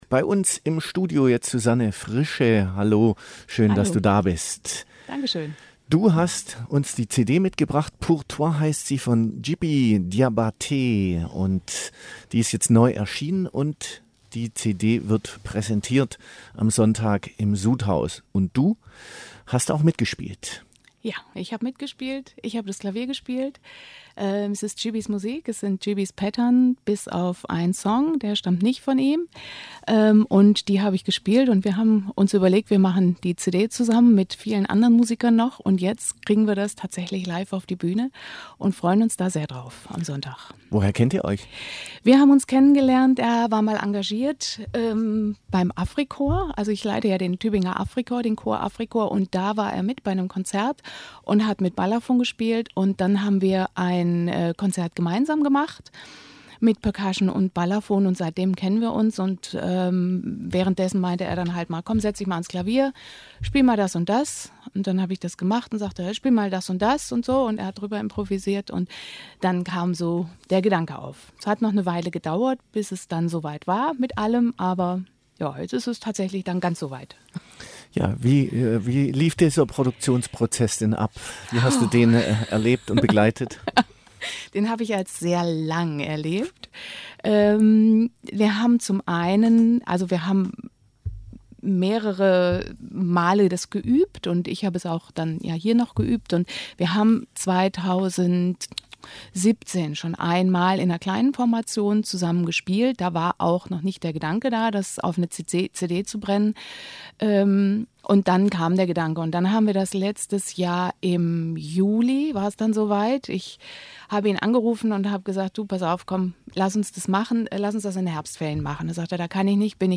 Audio Interview gekürzt Download